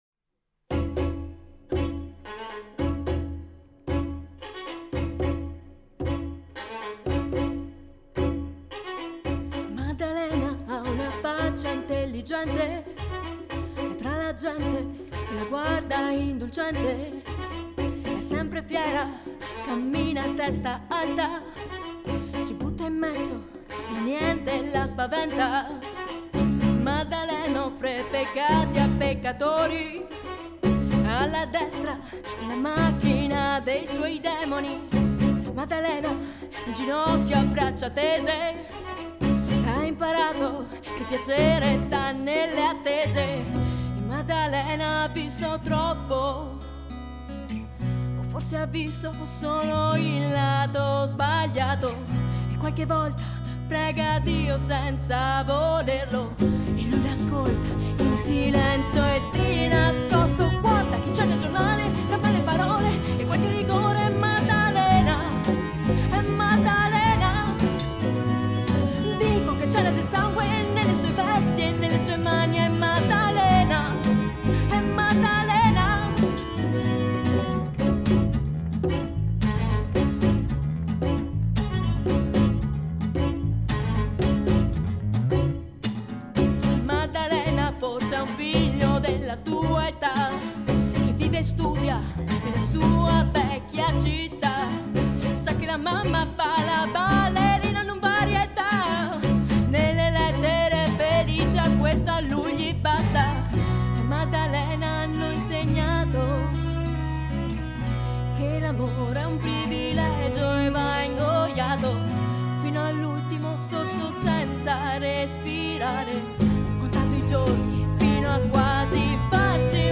gruppo folk-acustico